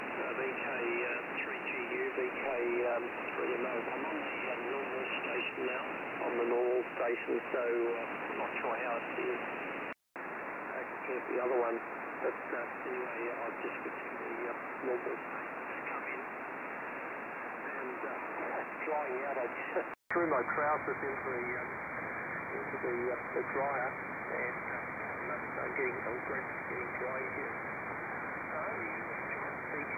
Second 00-10 > JRC NRD 545 DSP
Second 10-20 > Icom IC-R8600
Second 20-30 > Winradio G33DDC Excalibur Pro
Ham Radio in the 20m band. The Winradio G33DDC definitely has a stronger signal here than the other two receivers.